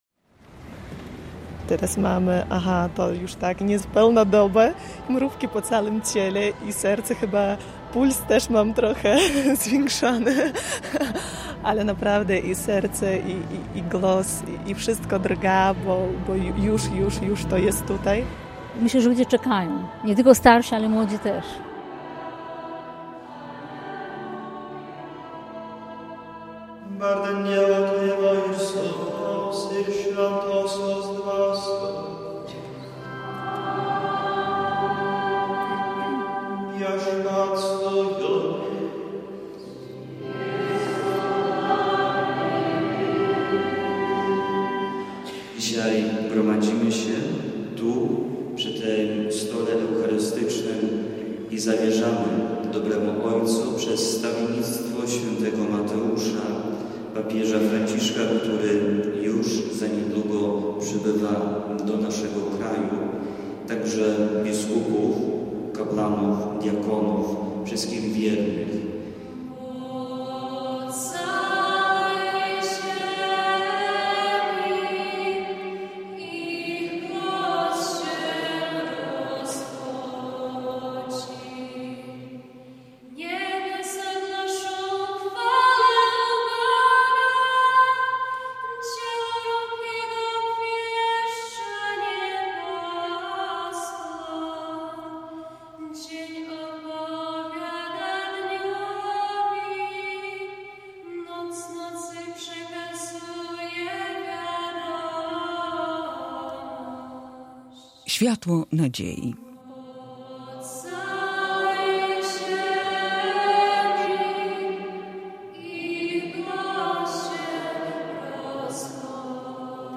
W chwili, gdy żegnamy papieża Franciszka, warto zatrzymać się na moment refleksji i przypomnieć sobie te wyjątkowe dni, kiedy był tak blisko nas. Reportaże ze Światowych Dni Młodzieży w Krakowie w 2016 roku oraz ze spotkania pielgrzymów w Wilnie w 2018 roku to nie tylko dokumentacja wydarzeń – to świadectwo wiary, jedności i miłości, jaką papież Franciszek obdarzał ludzi, jednocząc przy tym całe narody.